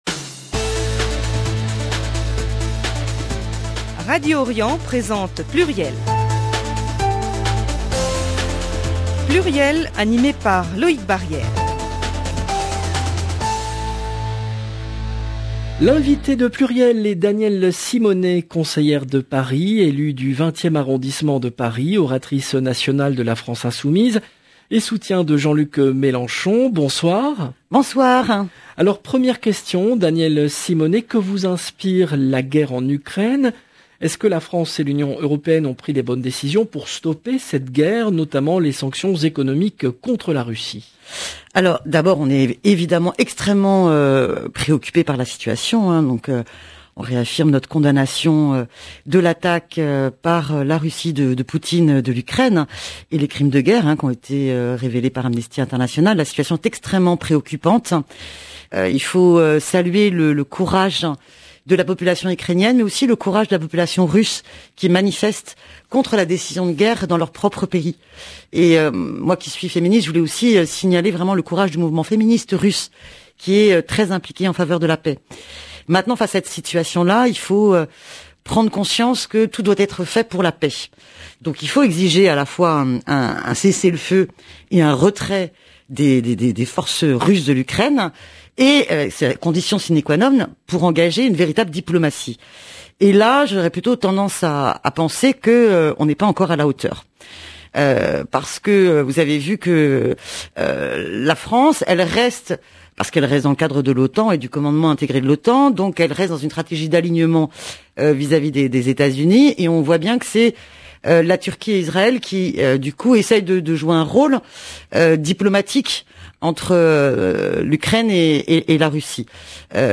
L’invitée de PLURIEL est Danielle Simonnet , conseillère de Paris, élue du 20e arrondissement de Paris, oratrice nationale de la France Insoumise, et soutien de Jean-Luc Mélenchon